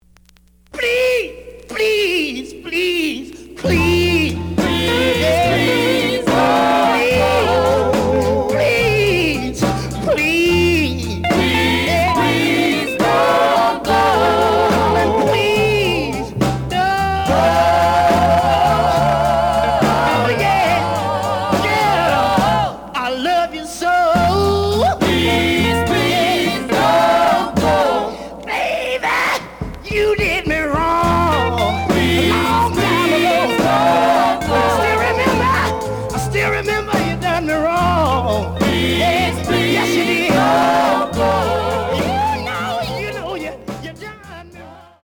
The audio sample is recorded from the actual item.
●Genre: Soul, 60's Soul
Some noise on parts of both sides.